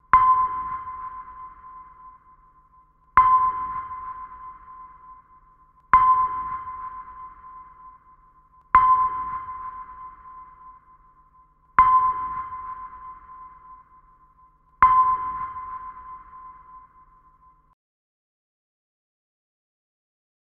Звуки эхолота
Чистый звук эхолота для монтажа